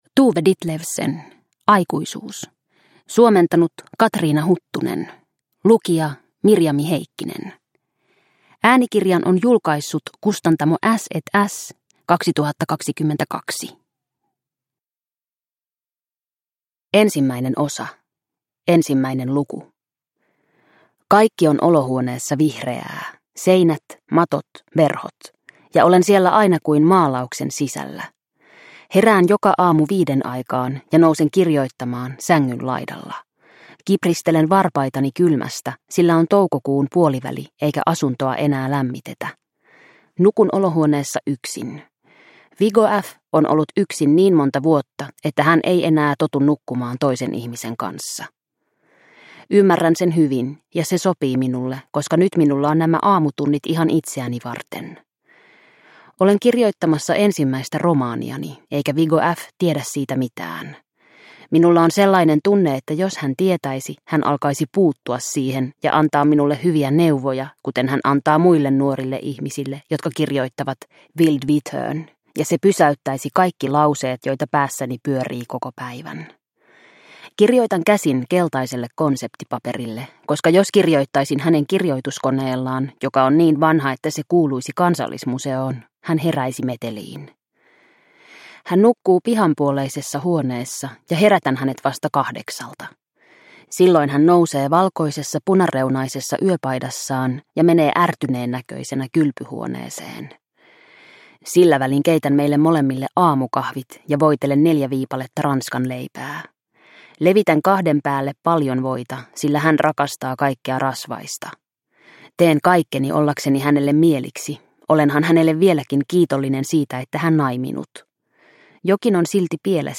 Aikuisuus – Ljudbok